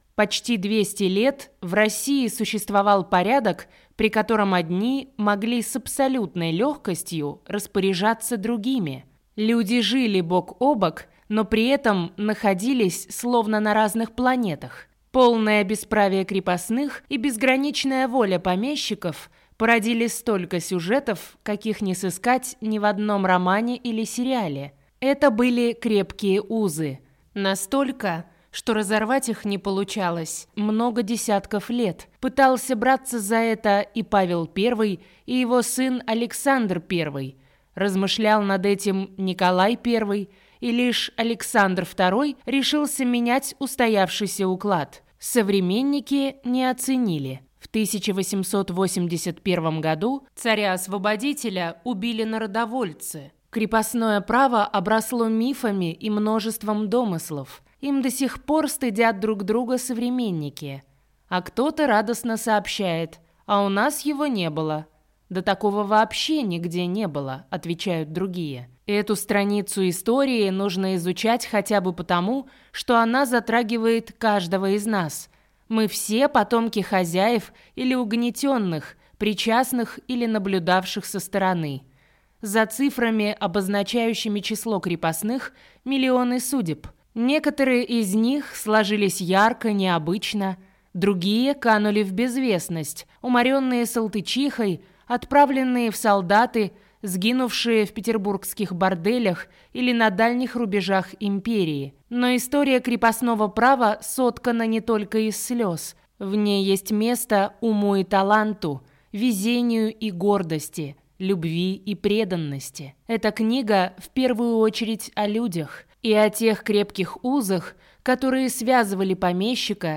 Аудиокнига Крепкие узы. Как жили, любили и работали крепостные крестьяне в России | Библиотека аудиокниг